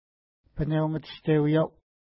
ID: 520 Longitude: -60.1133 Latitude: 53.3523 Pronunciation: pənew-mətʃtewi:ja:w Translation: Partridge Point Feature: point Explanation: This is a place where people would hunt ptarmigan in the winter.